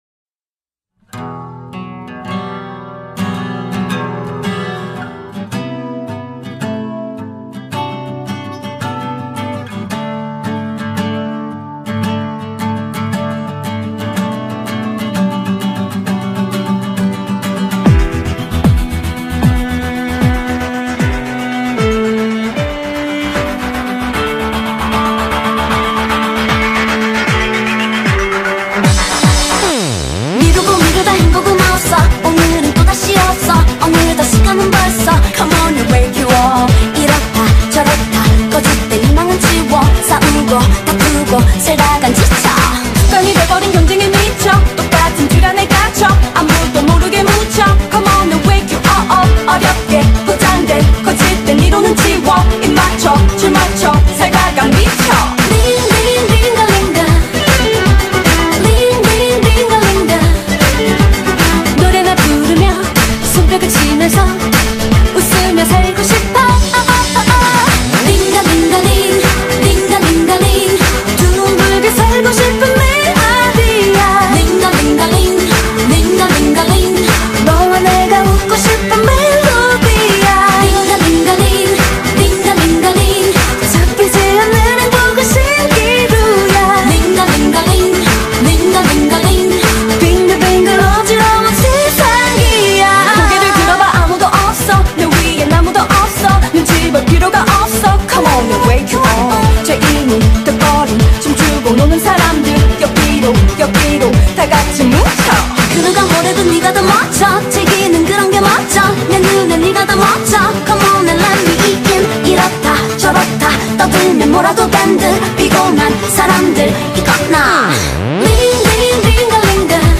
BPM153
Audio QualityCut From Video